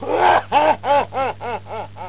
Laugh-male.wav